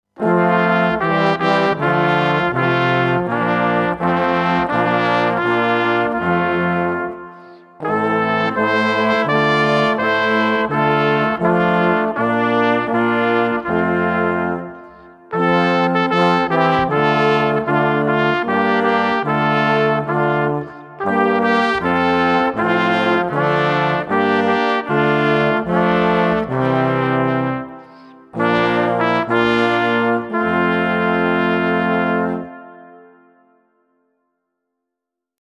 Wonderful settings of Luther songs in the typical swinging